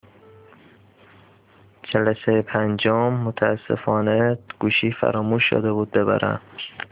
تدریس صوتی درس مدارالکتریکی 1